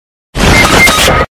Fortnite Death Earape